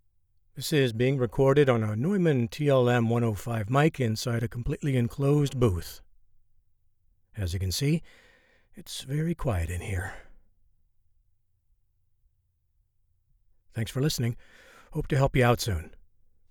Male
Studio Quality Sample
Enclosed Booth - Neumann Mic